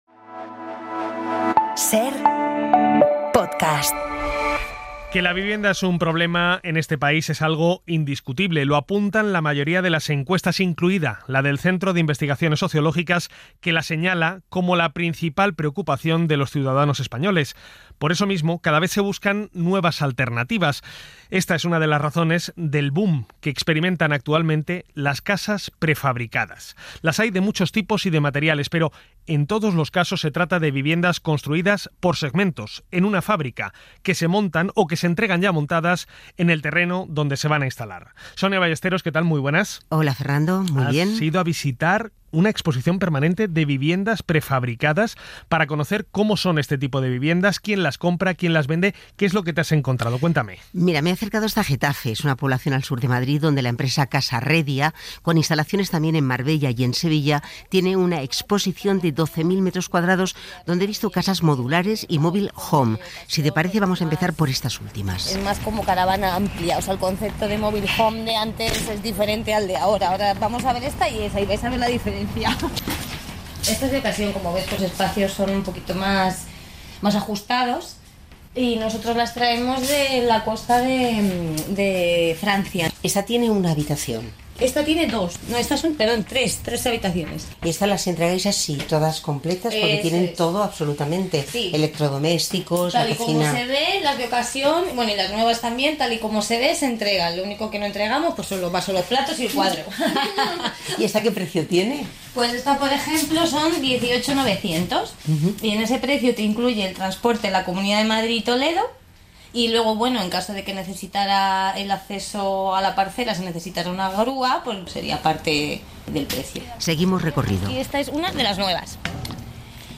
Las casas prefabricadas y mobile homes se consolidan como una alternativa más asequible a la vivienda tradicional en un mercado tensionado. Averiguamos sus pros y contras durante la visita a una exposición permanente en donde sabremos quién las compra y con qué intención.